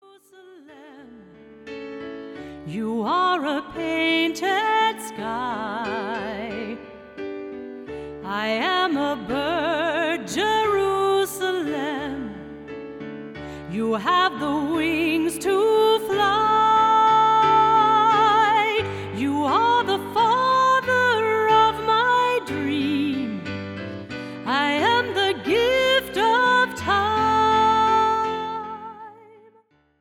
popular adult contemporary worship songs